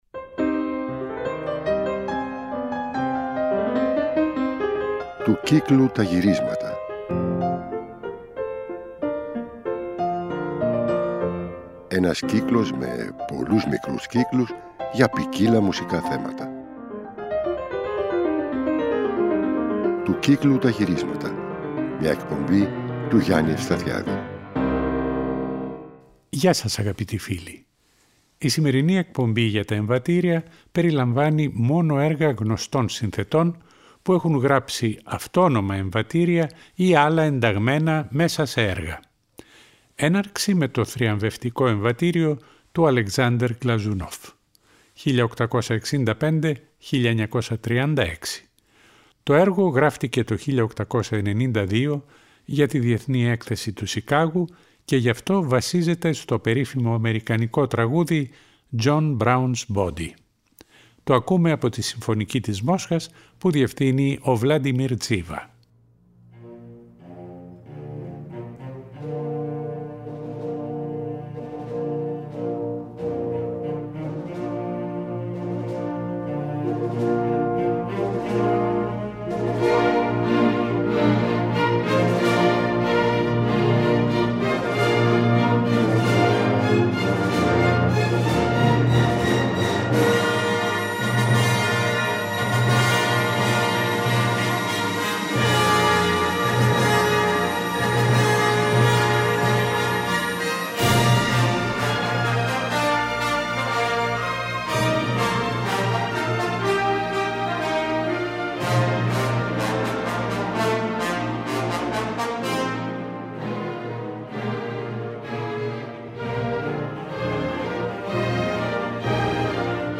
Αυτή η εκπομπή για τα εμβατήρια περιλαμβάνει μόνο έργα γνωστών συνθετών που έχουν γράψει αυτόνομα εμβατήρια, ή άλλα ενταγμένα μέσα σε έργα.